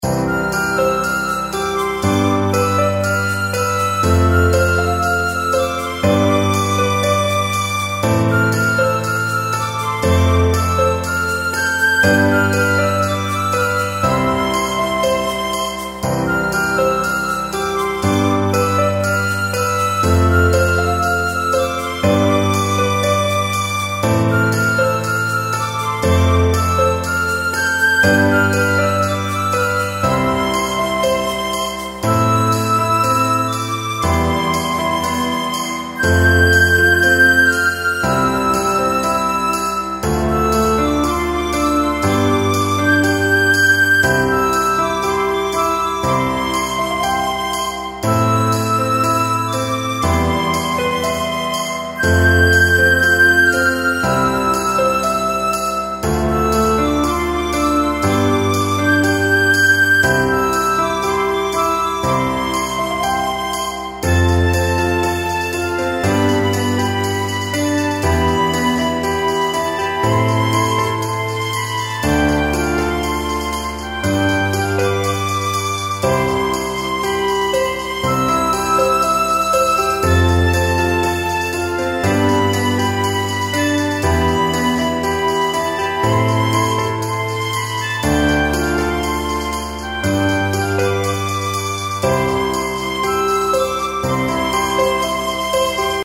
ピアノとフルートを中心に構成された、夏の情景を描く爽やかなインストゥルメンタルBGMです。
• 使用楽器：ピアノ、フルート、軽やかなパーカッション
• BPM：120
• 雰囲気：爽やか・明るい・優しい・涼しげ・ナチュラル
• パーカッションは軽めのShaker/Claves系で、空間を埋めすぎないよう設計
• ステレオ感：フルートをセンターやや前面／ピアノは左寄りに軽く配置